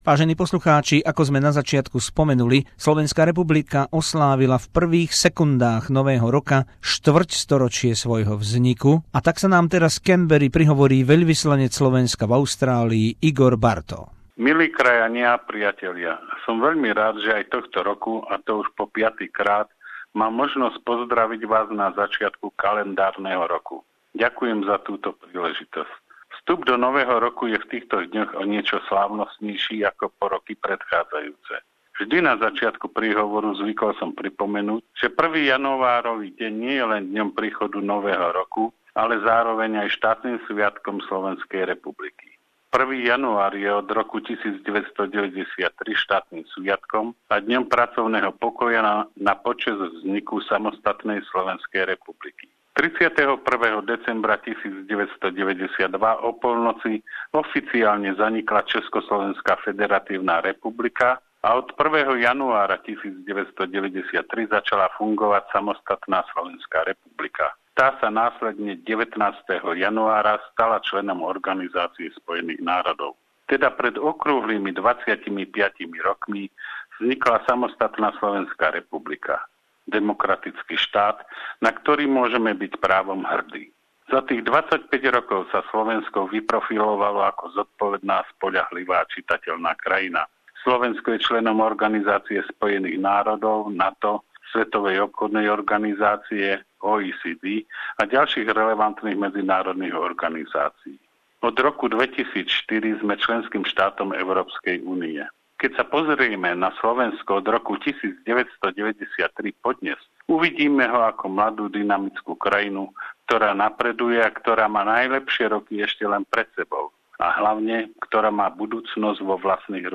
Novoročný príhovor krajanom v Austrálii veľvyslanca Igora Bartha z Canberry